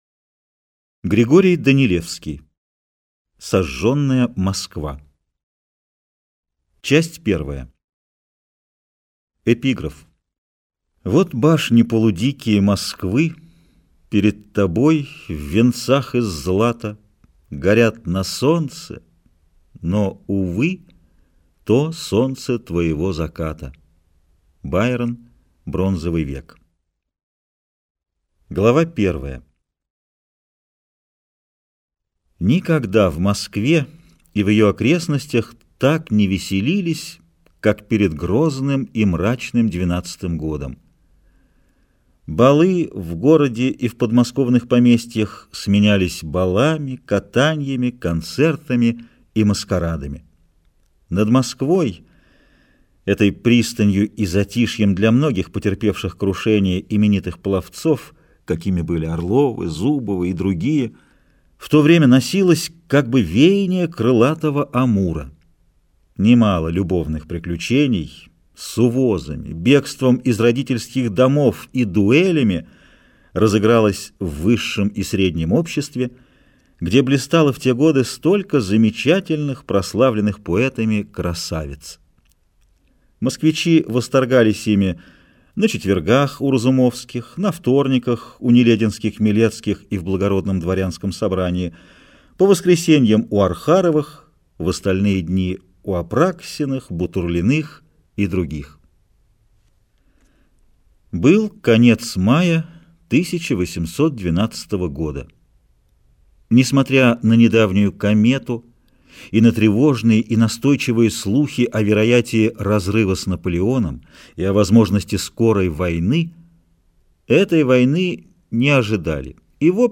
Аудиокнига Сожженная Москва. Княжна Тараканова | Библиотека аудиокниг